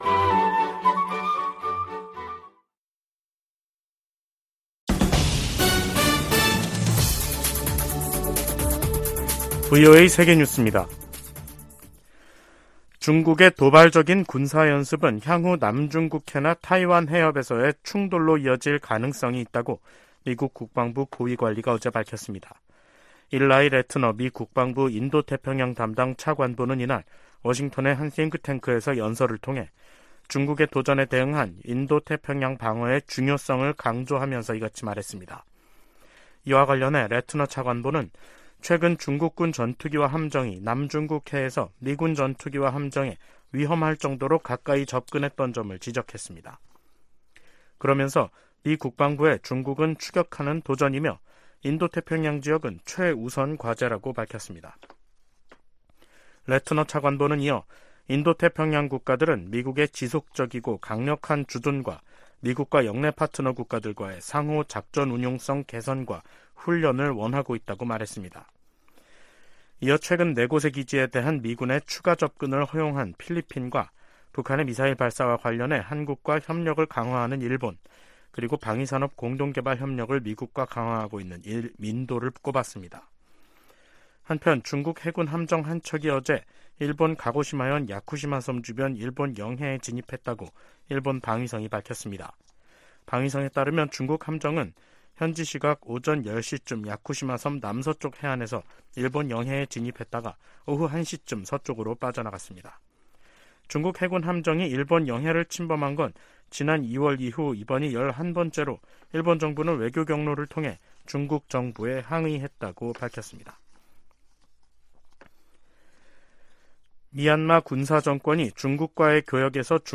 VOA 한국어 간판 뉴스 프로그램 '뉴스 투데이', 2023년 6월 9일 2부 방송입니다. 미 국무부가 한국의 새 국가안보전략을 환영한다고 밝혔습니다. 중국과 북한의 핵무력 증강에 대응해 미국도 핵전력을 현대화하고 있다고 국방부 고위 관리가 밝혔습니다. 북한이 군사정찰위성 발사 실패 이후 국제사회 비판 여론에 예민하게 반응하면서 주민들에겐 알리지 않고 있습니다.